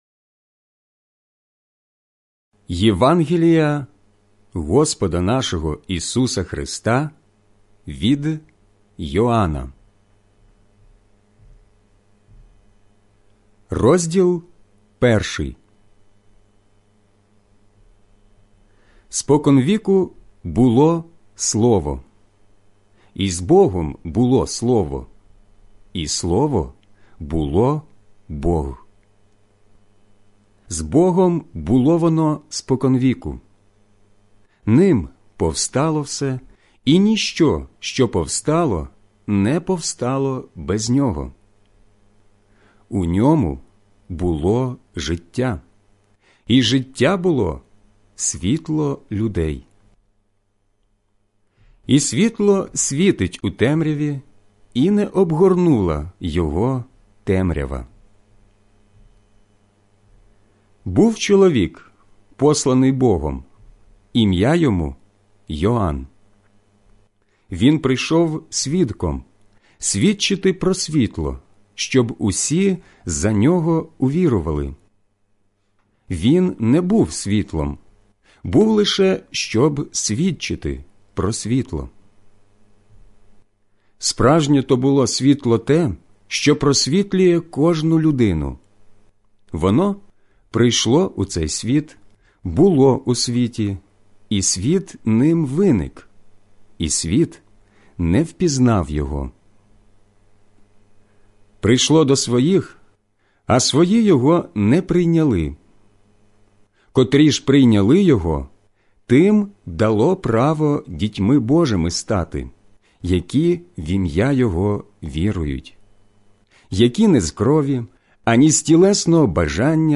ukrainian-audio-bible-10478-genesis-1.mp3
• Voice only Bible reading